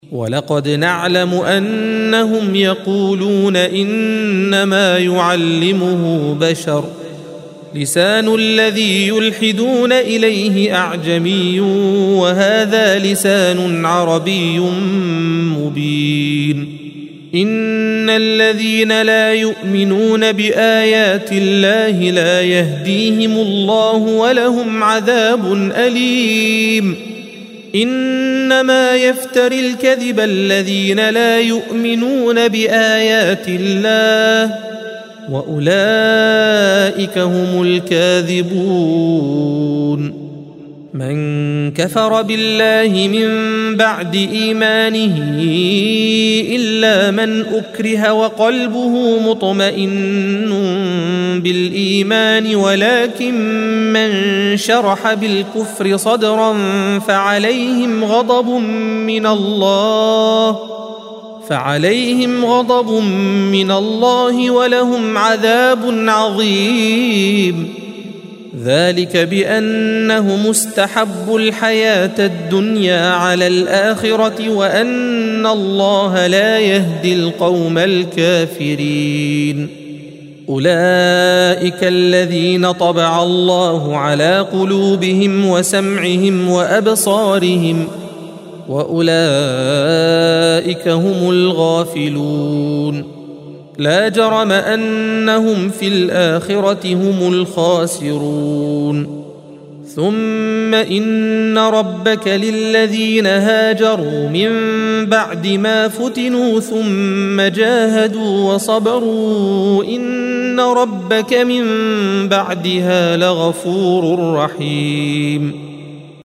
الصفحة 279 - القارئ